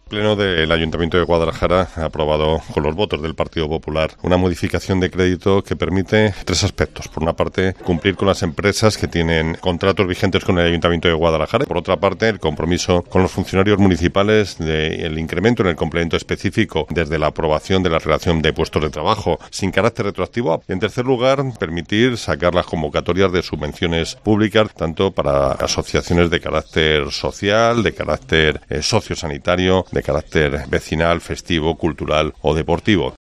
Román, ante los micrófonos de COPE Guadalajara, se ha manifestado sobre lo que supone la aprobación con los votos del Partido Popular, de la modificación de créditos y subvenciones